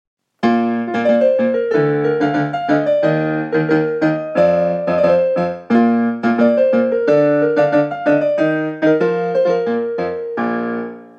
広島東洋カープ #2 東出輝裕 応援歌